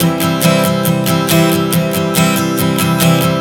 Strum 140 C 02.wav